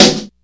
Kidnplaysnare.wav